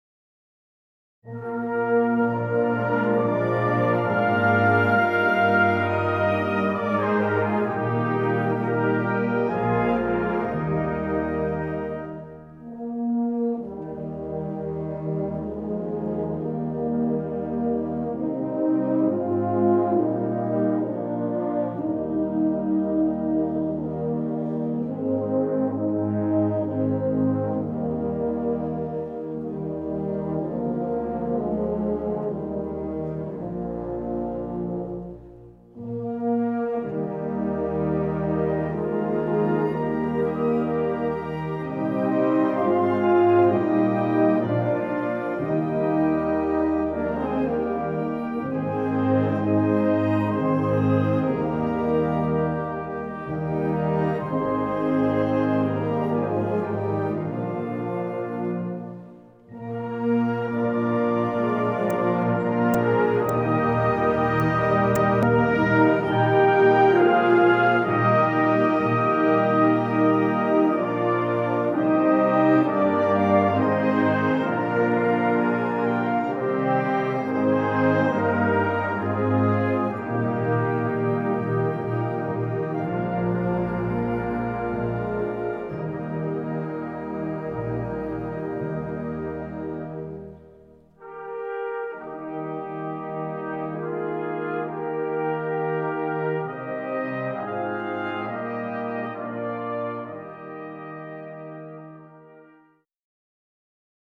Gattung: Choralsammlung
Besetzung: Blasorchester